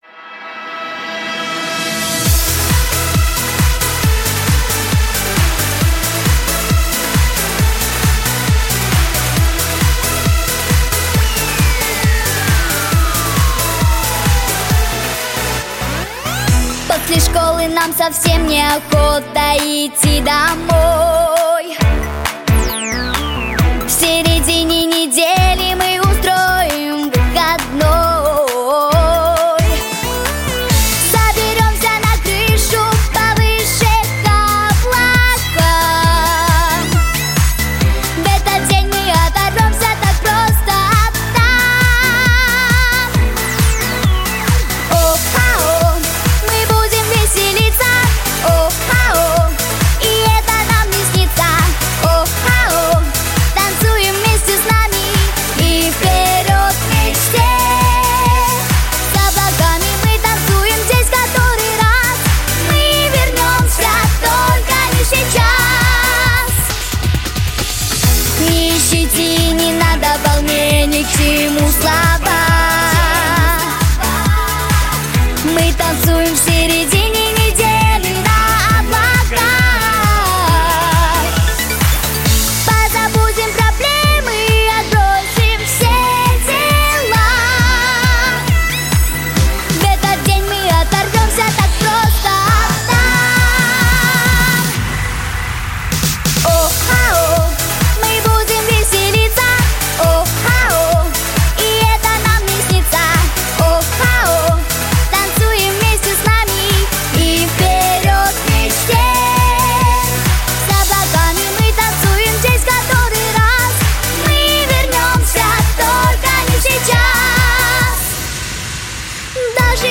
• Категория: Детские песни
детская дискотека, танцы